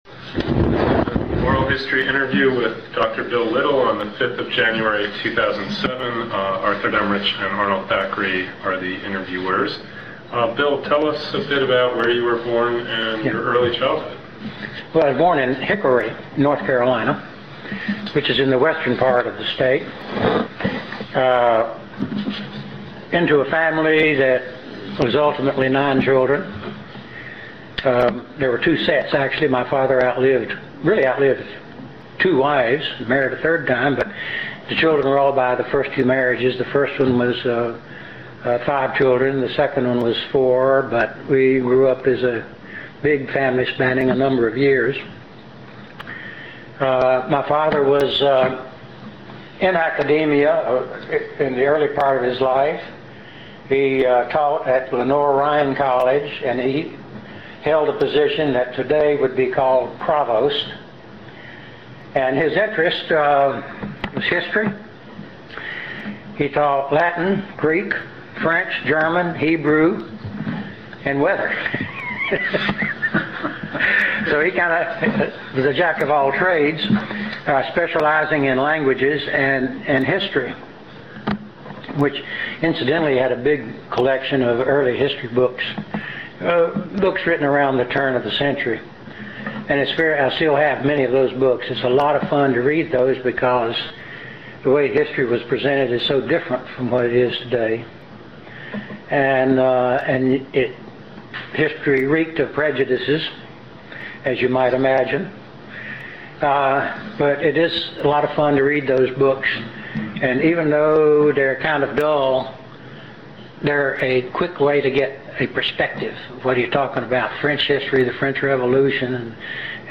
Place of interview North Carolina--Chapel Hill
Genre Oral histories